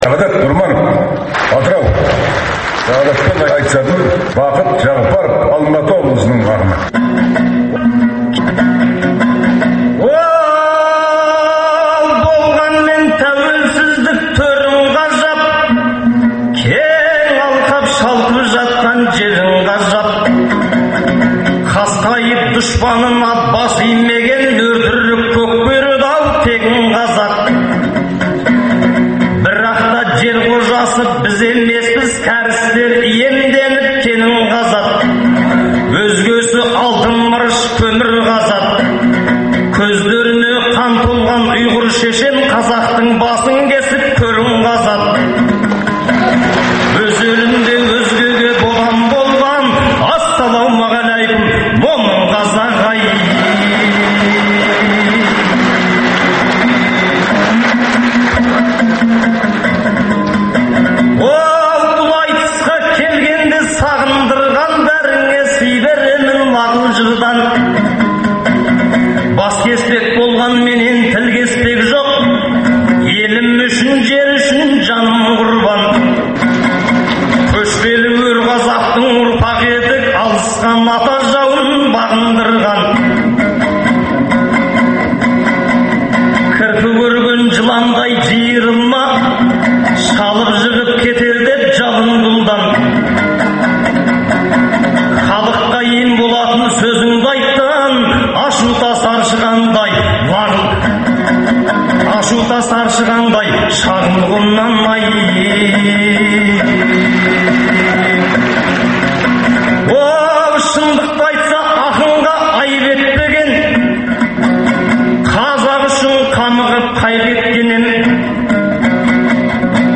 Айтыстан үзінділер беріледі, ақындар айтысының үздік нұсқалары тыңдарменға сол қалпында ұсынылып отырады.